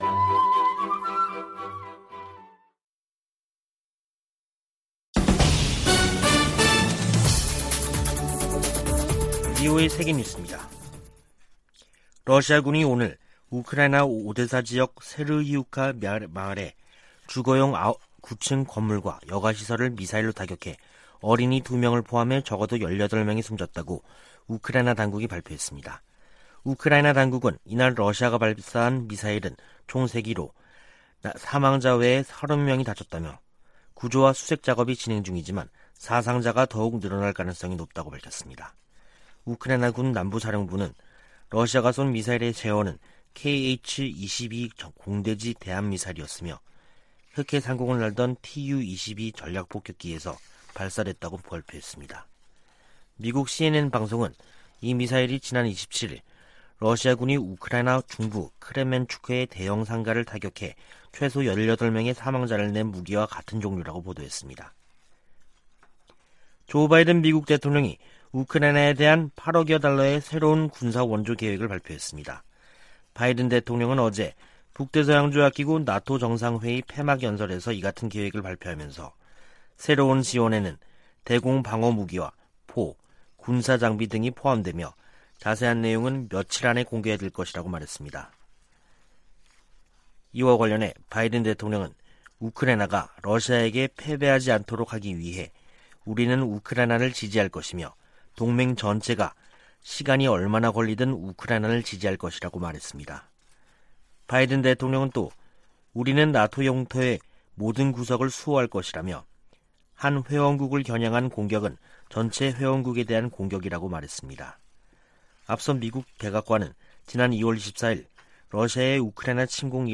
VOA 한국어 간판 뉴스 프로그램 '뉴스 투데이', 2022년 7월 1일 2부 방송입니다. 북대서양조약기구(NATO·나토) 정상회의가 막을 내린 가운데 조 바이든 미국 대통령은 ‘역사적’이라고 평가했습니다.